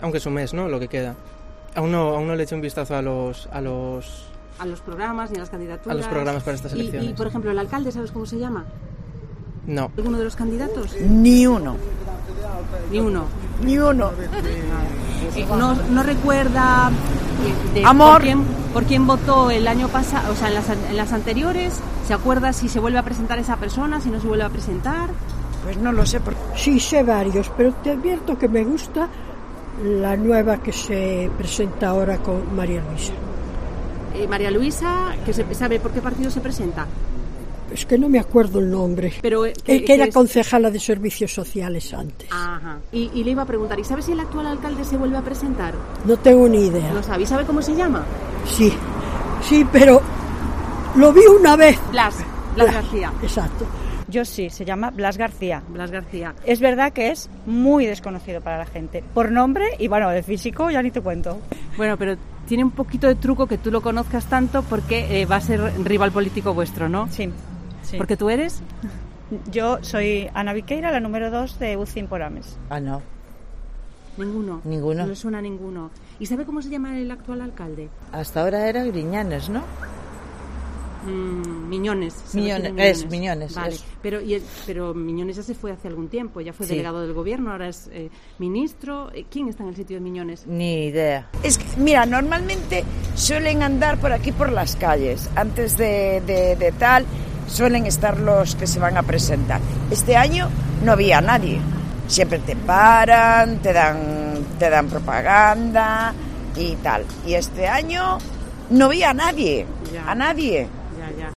Callejeando por MIlladoiro, apenas encontramos residentes que sepan identificar candidatos al 28M